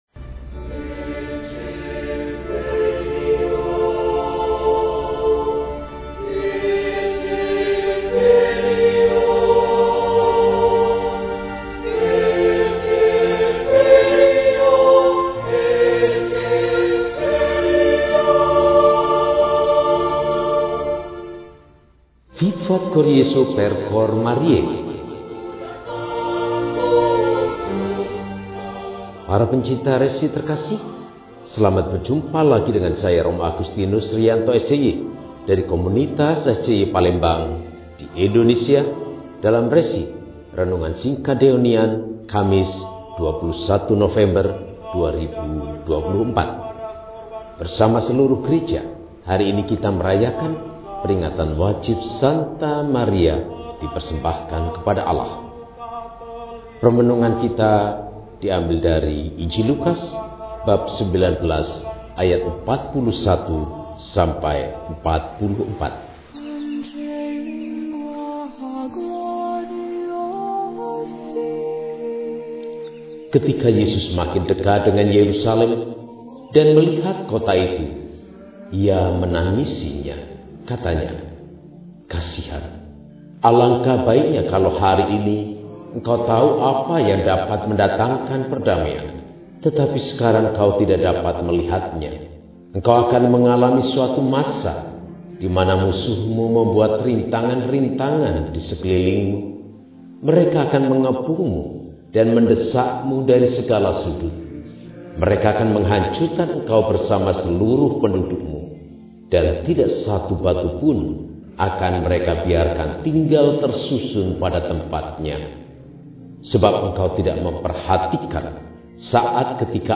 Maria Dipersembahkan kepada Allah – RESI (Renungan Singkat) DEHONIAN